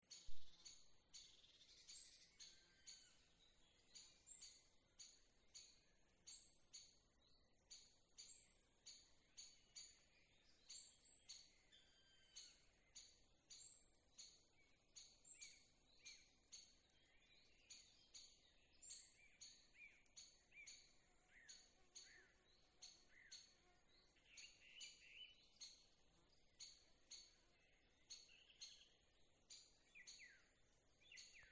Birds -> Woodpeckers ->
Black Woodpecker, Dryocopus martius
StatusSpecies observed in breeding season in possible nesting habitat